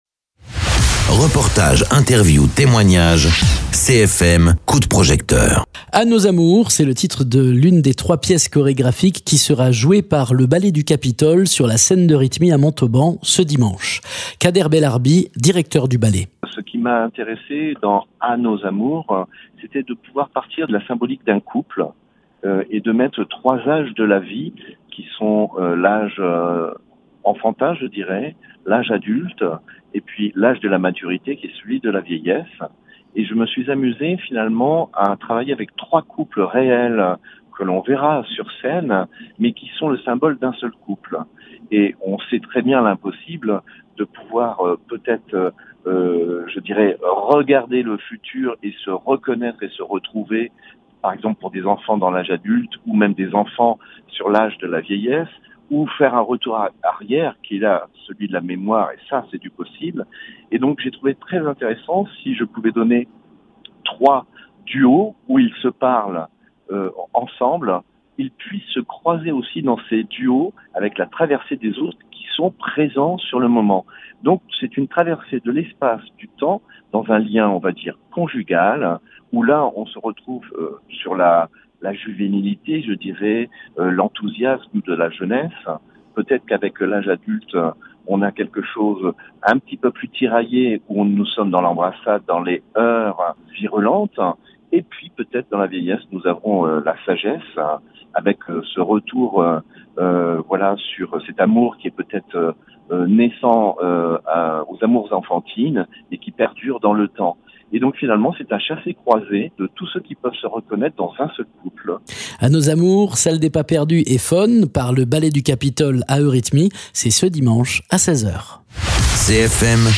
Interviews
Invité(s) : Kader Belarbi, directeur du ballet